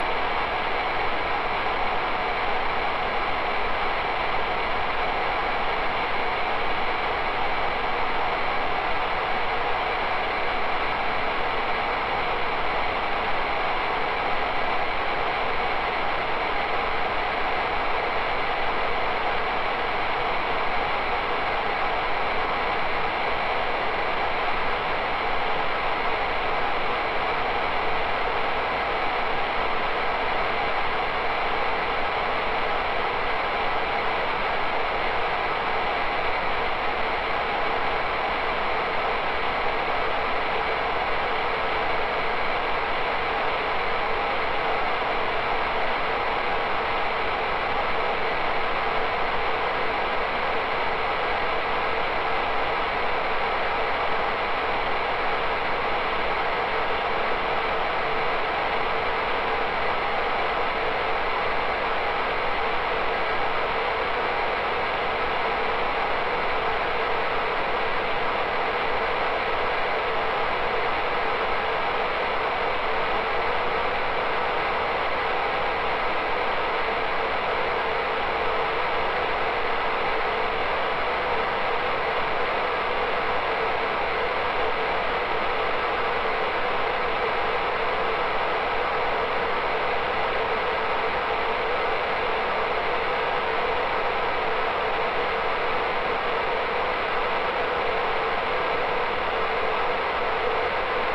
whitenoise.wav